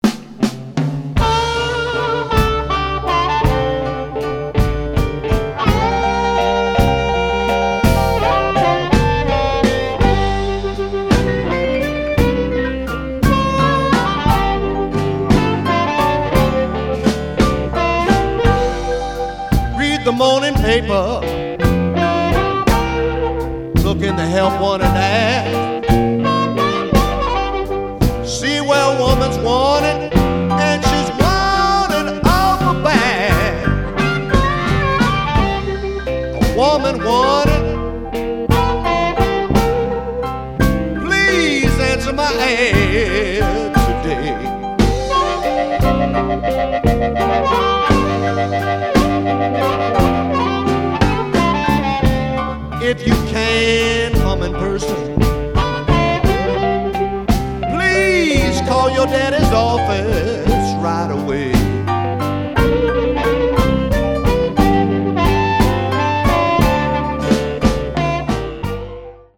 Chicago influenced harp playing